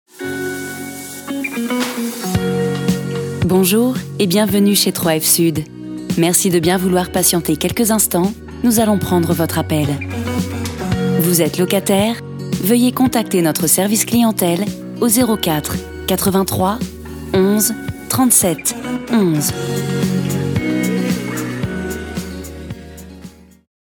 Attente et répondeur téléphonique pour le compte de Immobilière 3F, premier bailleur social français.
3F_Sud_Attente.mp3